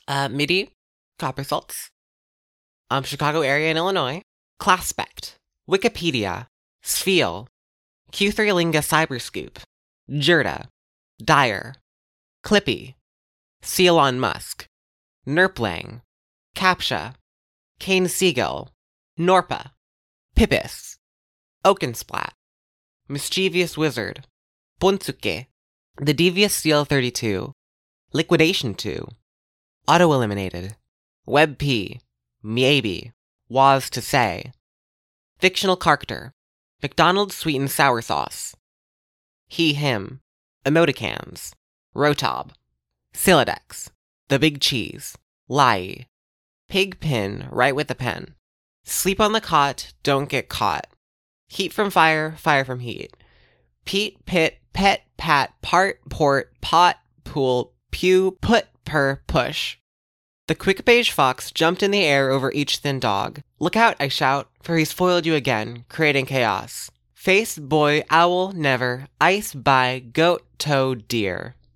The Wiki Camp Accent Challenge 2
Recordings of weselves pronouncing those words.
ˈklaspɛʔktʰ
ˌwɪkəˈpijɾijə
pʰɪg pʰɪn ‖ ɻəj wɪθ ə pʰɛn
slijp ɐn ðɘ käətʰ ‖ dəwŋ kʰɪʔ kʰä̹ɜt̚